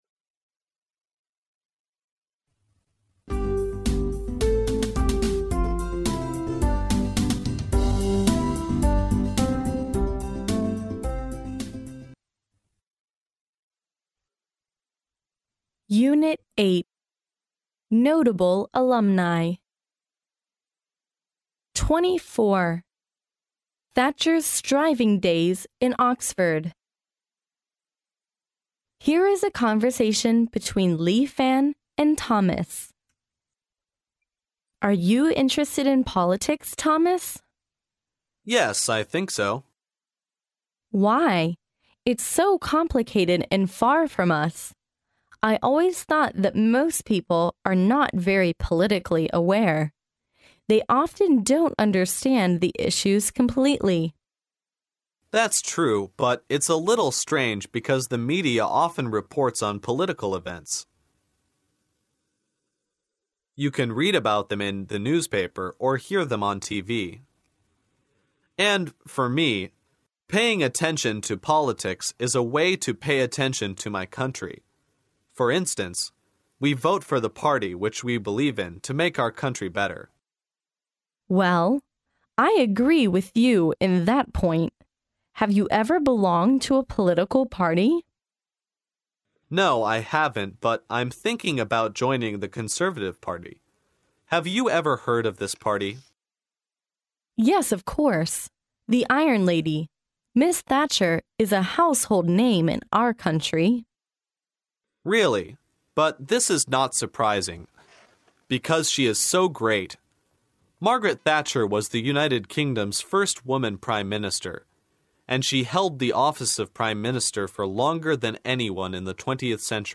牛津大学校园英语情景对话24：撒切尔夫人的牛津寒窗（mp3+中英）